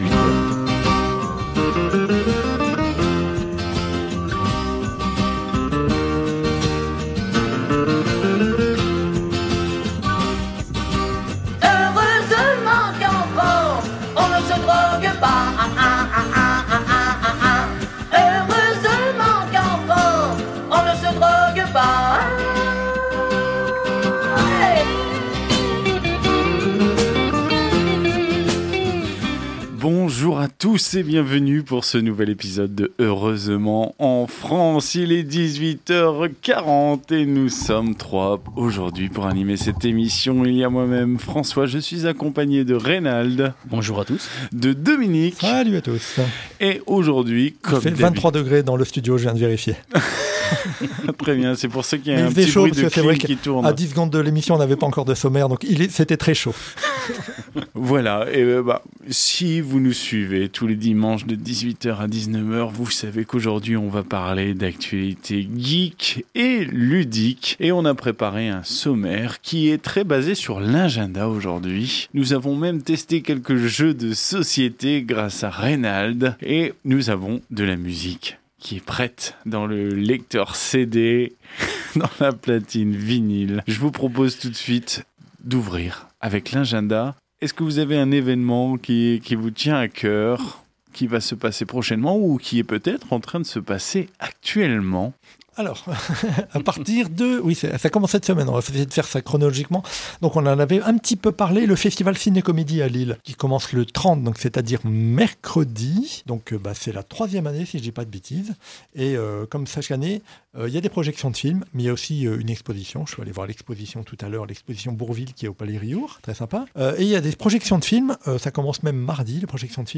Au sommaire de cet épisode diffusé le 27 septembre 2020 sur Radio Campus 106.6 :
– l’agenda ludique et geek – des jeux de plateau – de la musique écossaise